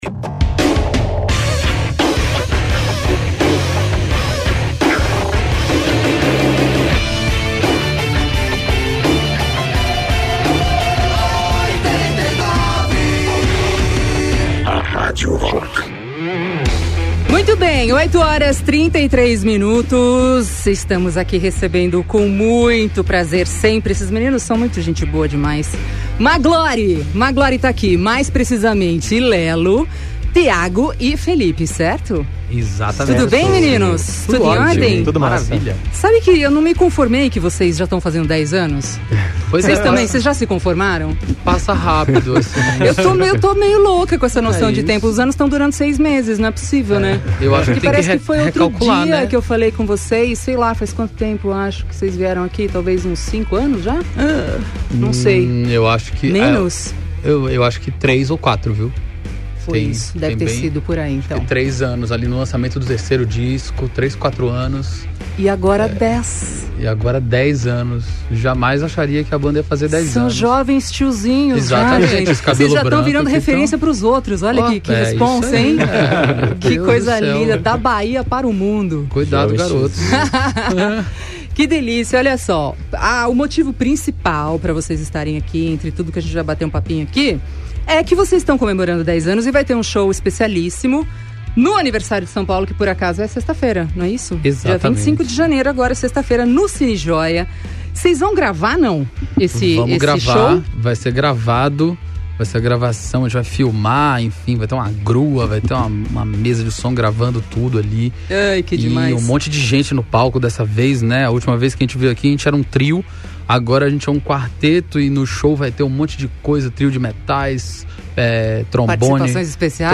Além disso, O Maglore relembrou diversas histórias que marcaram esses 10 anos de carreira e destacaram o seu recente álbum, Todas As Bandeiras , lançado em 2017. Ouça a entrevista na íntegra no player abaixo: https